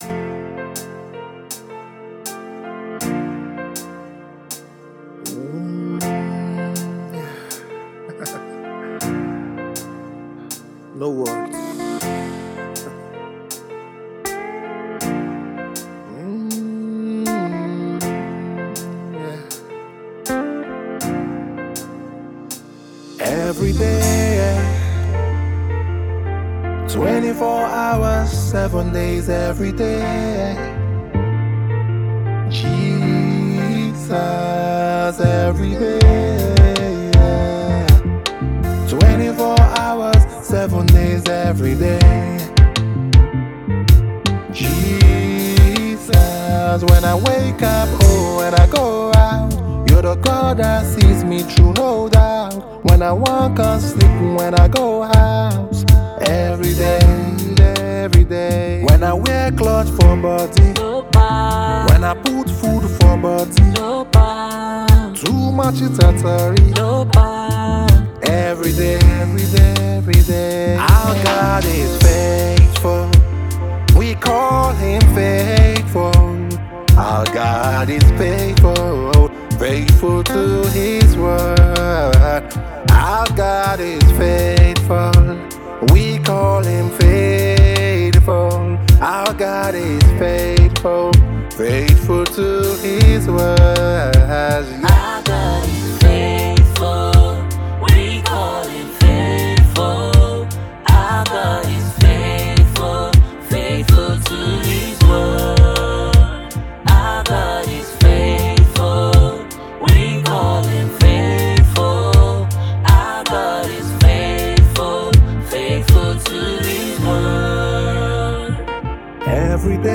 gospel
song of worship